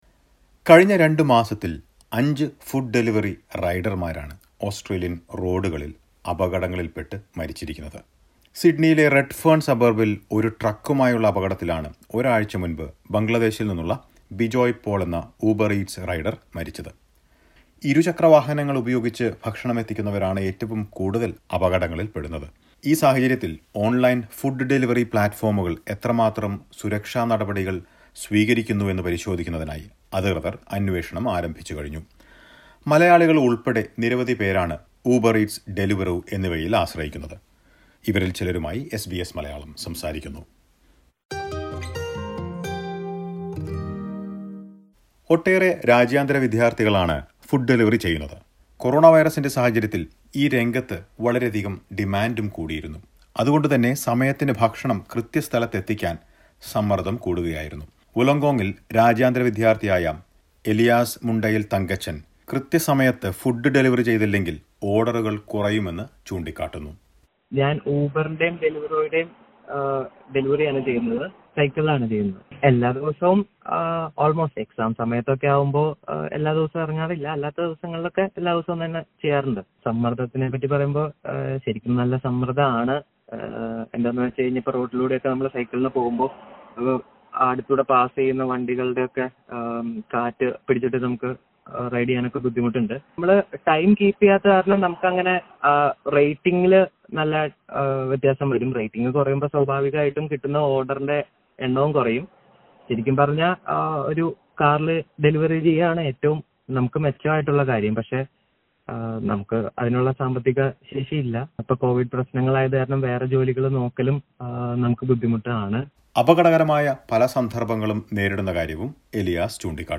A few Malayalee delivery riders share their concern